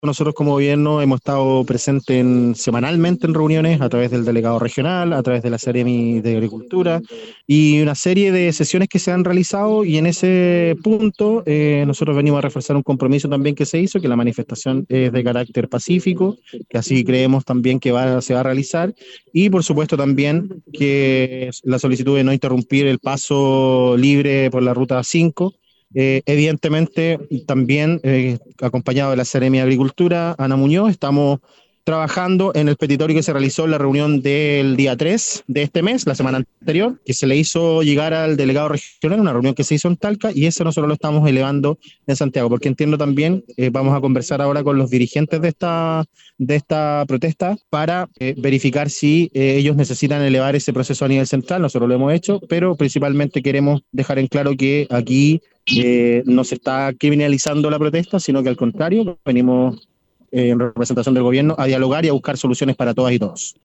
En tanto, el delegado presidencial provincial, quien se trasladó hasta el lugar de la manifestación, también entregó su opinión respecto a las demandas de los agricultores.